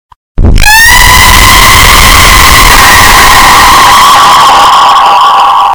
Aaaaaaaaa Loud Asf Sound Effect Download: Instant Soundboard Button
Sound Effects Soundboard22 views